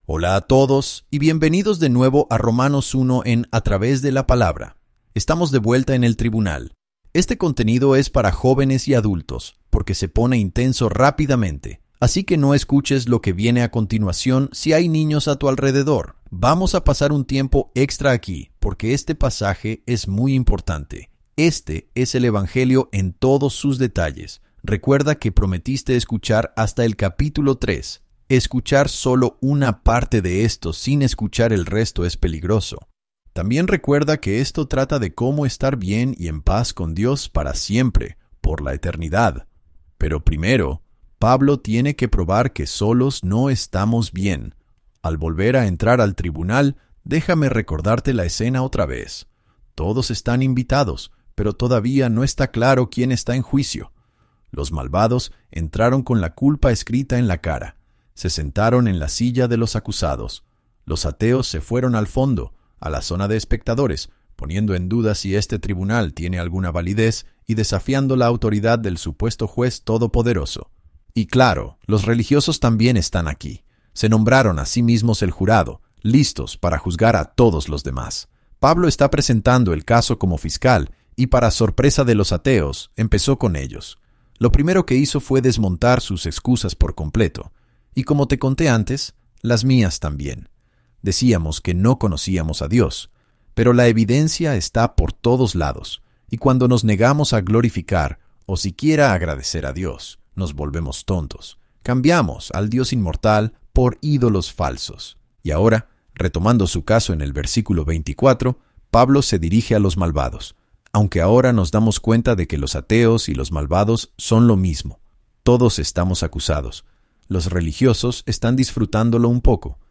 Cada capítulo aporta nuevas perspectivas y comprensión mientras tus maestros favoritos explican el texto y hacen que las historias cobren vida.